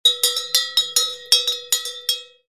На этой странице собраны звуки коровьего колокольчика — натуральные и атмосферные записи, которые перенесут вас на деревенское пастбище.
Звук коровьего колокольчика - Пример